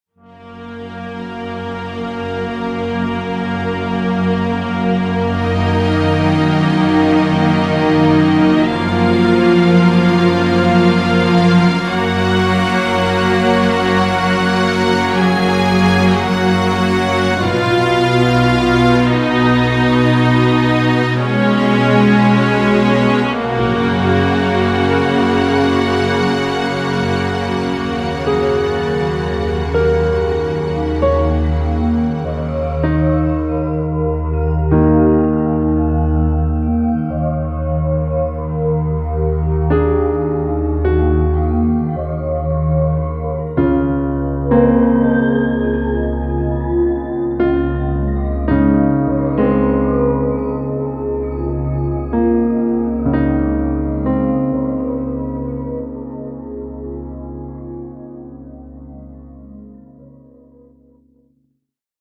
Orchestral uplift to atmospheric regret
uplift-to-regret.MP3